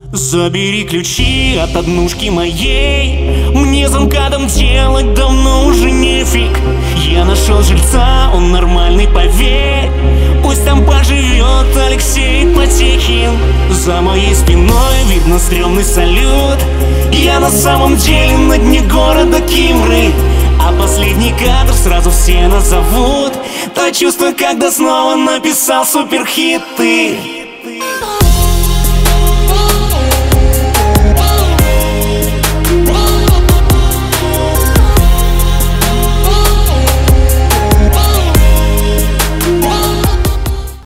• Качество: 320, Stereo
поп
dance
попса